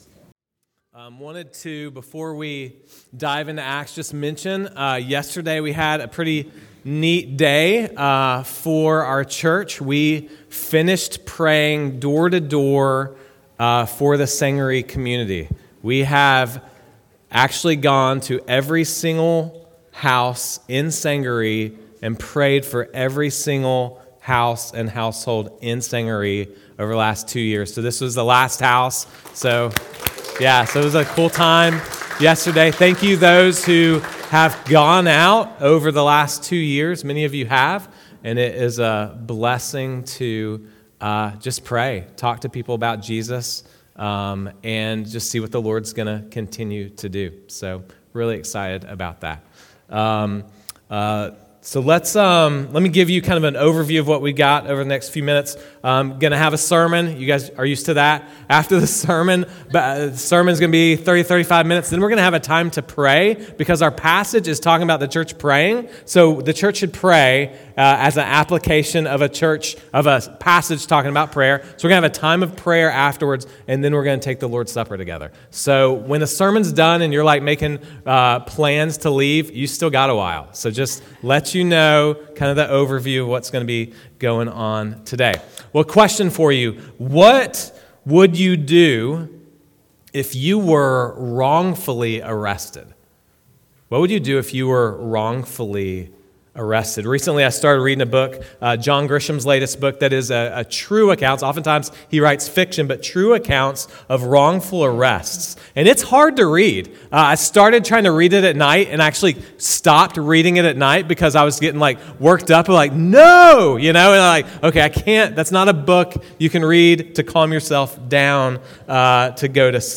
Sunday-Service.mp3